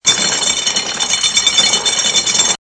CHQ_SOS_cage_lower.ogg